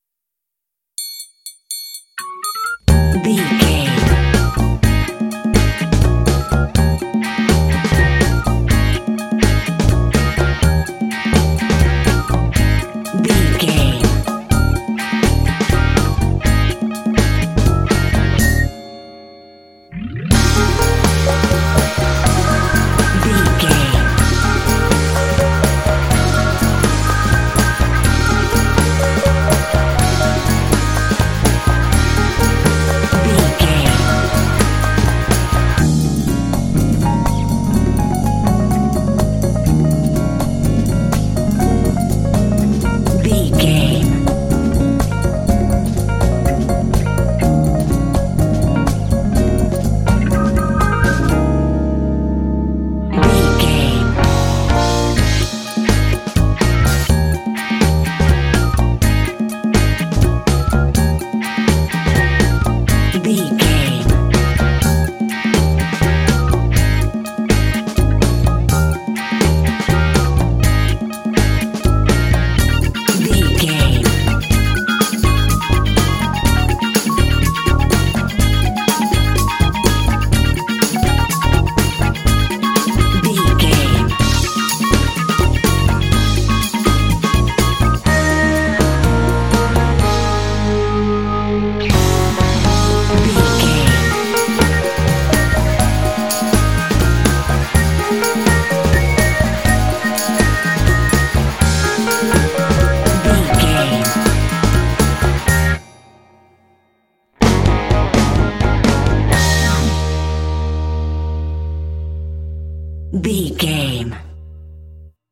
Uplifting
Mixolydian
E♭
bouncy
happy
groovy
bright
percussion
electric organ
bass guitar
electric guitar
drums
alternative rock
indie